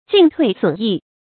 進退損益 注音： ㄐㄧㄣˋ ㄊㄨㄟˋ ㄙㄨㄣˇ ㄧˋ 讀音讀法： 意思解釋： 增減變動。